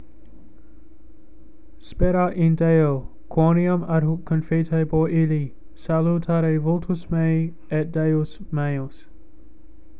Spair - ah    in     day - o,  kwo-nee-ahm    ad - hook   kon-fee-tay-bor    ill-lee